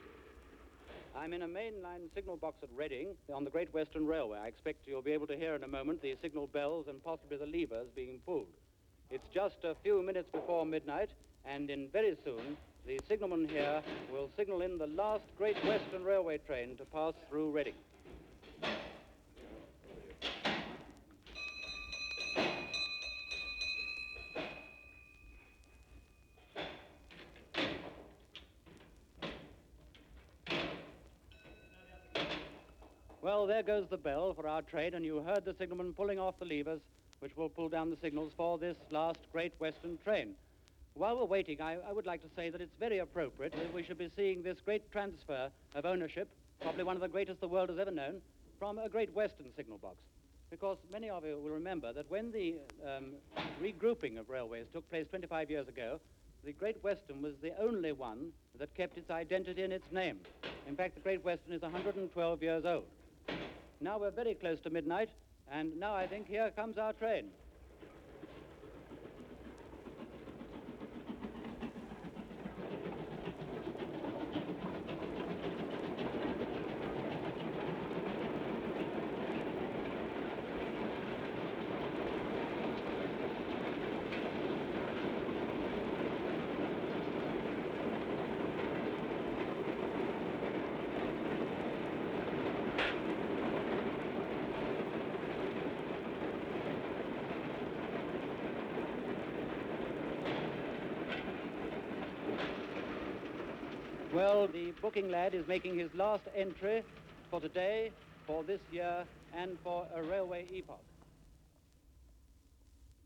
This historic 1947 New Year’s Eve sound recording in a signal box at Reading, Berkshire, 36 miles from Paddington mentions that it was a few minutes before midnight.
The bell code of four beats consecutively (“Is line clear for an express passenger train”) received from the next box down the line can be heard. It is answered with four consecutive beats. We then hear the two beats on the bell for the train entering the signal box’s block section, and this is answered with two beats. The two beats on the bell plunger inside the signal box can be heard and this acknowledges to the signal box which sent it that it was received and correctly repeated. The sounds of signal levers being pulled and replaced in the metal frame can be heard.